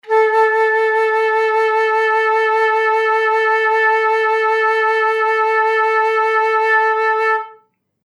interactive-fretboard / samples / flute / A4.mp3